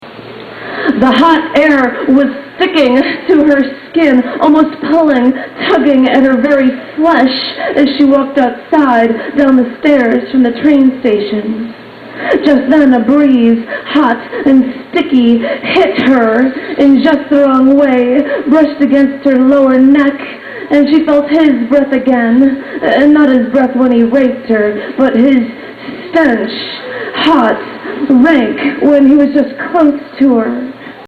Below are mp3 files from a September 23 2003 evening of poetry.
Live at the Cafe - 3 disc set